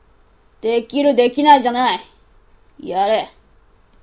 基本低音｡かっこよく聞こえると良いのですが｡